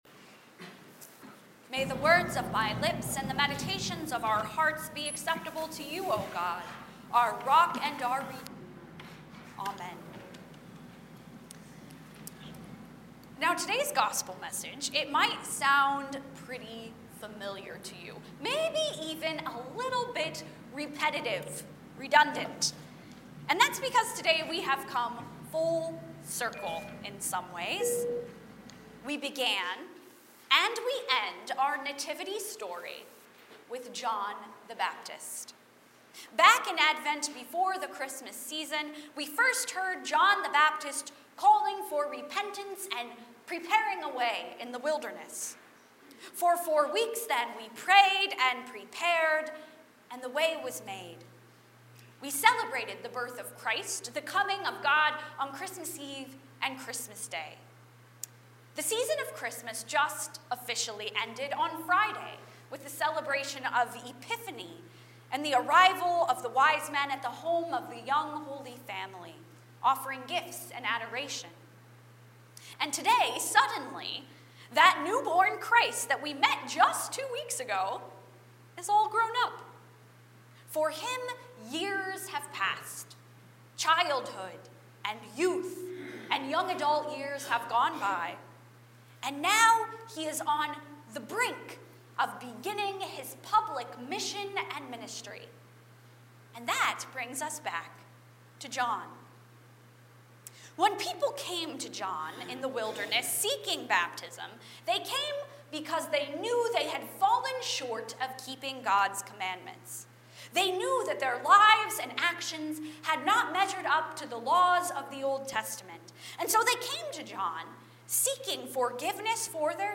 Weekly Services Passage: Matthew 3:13-17, Isaiah 42:1-9 Service Type: Sunday Morning 10:30 %todo_render% « Jesus is Born Again!